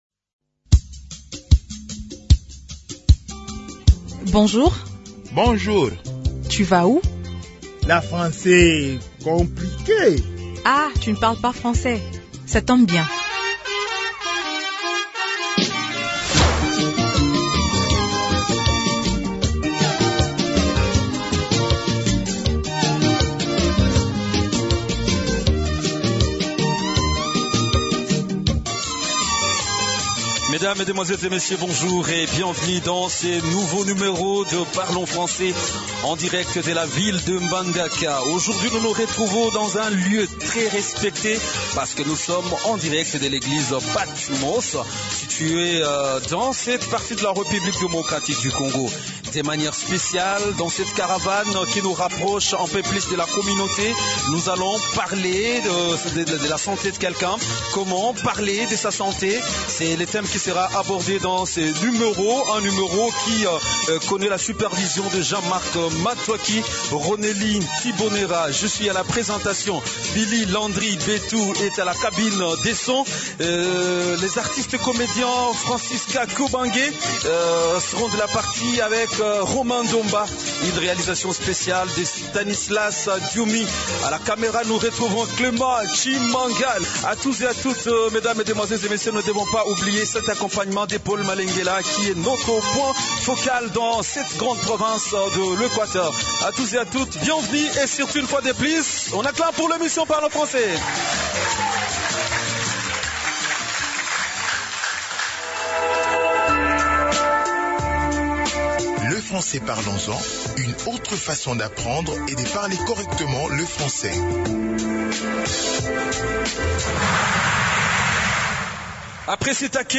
Nous avons le plaisir de vous présenter le troisième numéro de notre émission Parlons français, diffusé en direct depuis la province de l'Équateur. Dans cet épisode, notre équipe rencontre des débutants en français fonctionnel à Mbandaka, et leur apprend des expressions courantes pour parler de leur santé.